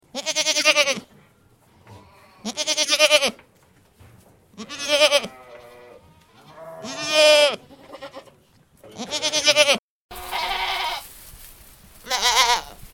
جلوه های صوتی
دانلود صدای بز 8 از ساعد نیوز با لینک مستقیم و کیفیت بالا